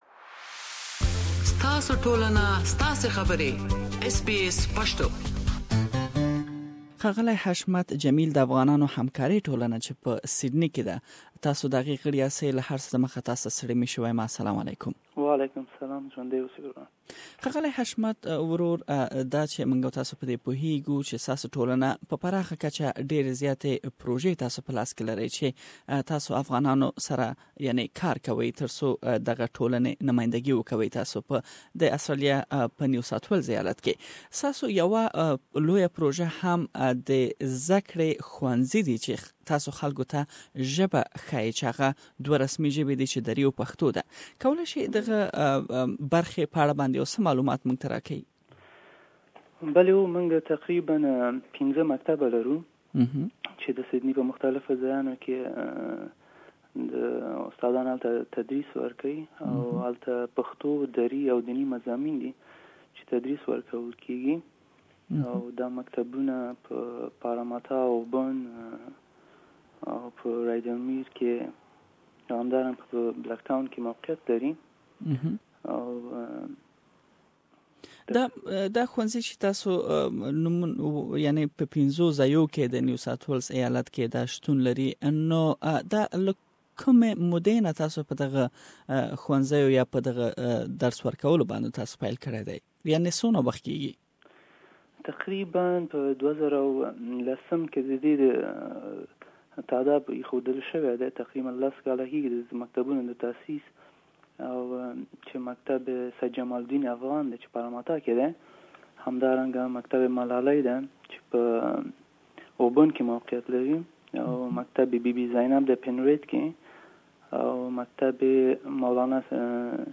SBS RADIO